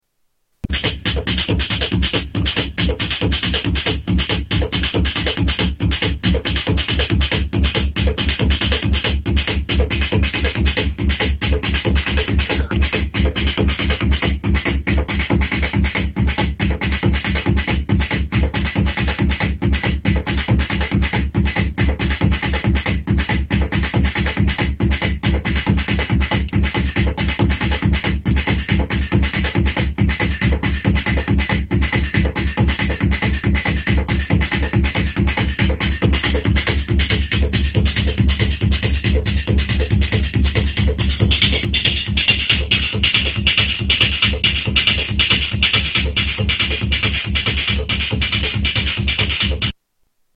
Jomox xbase09 Test Run
Category: Sound FX   Right: Personal
Tags: Sound Effects JoMoX Sounds JoMoX XBase AirBase